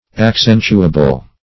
Accentuable \Ac*cen"tu*a*ble\, a. Capable of being accented.